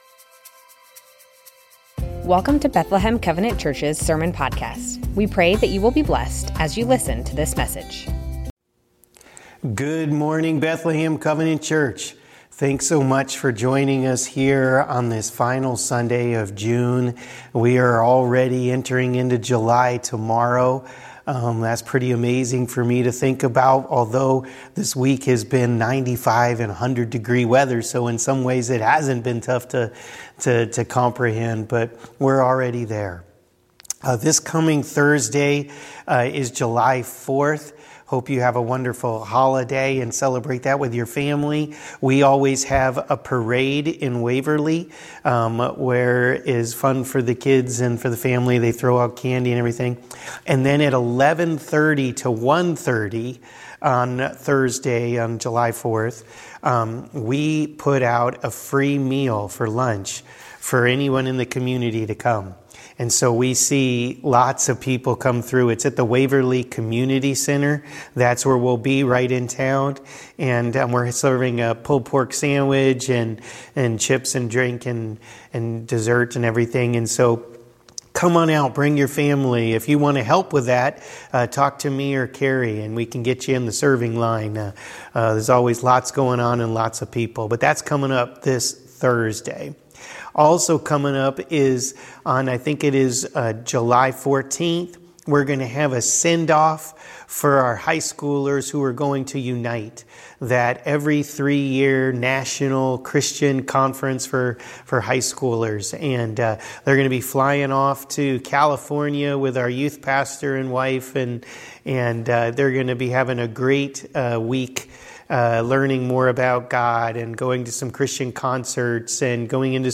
Bethlehem Covenant Church Sermons James - How we do business Jun 30 2024 | 00:32:39 1x 00:00 / 00:32:39 Subscribe Share Spotify RSS Feed Share Link Embed